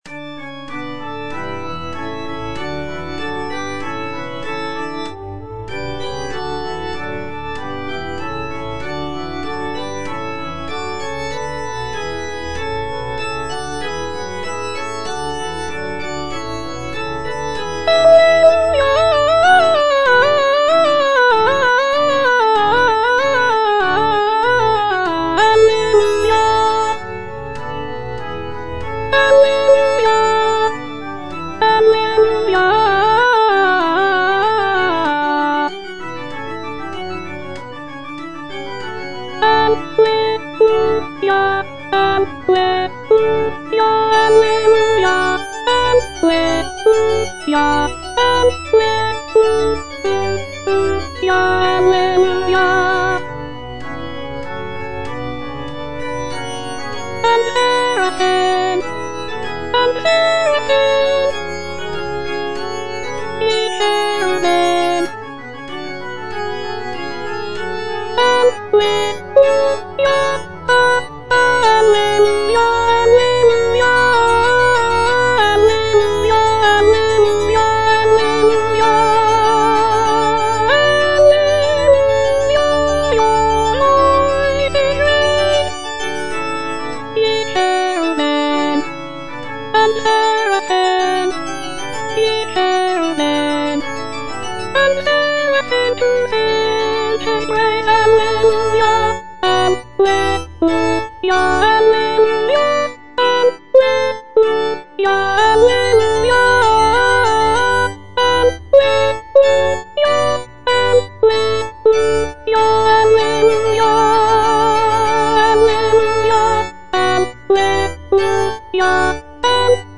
(A = 415 Hz)
Soprano (Voice with metronome) Ads stop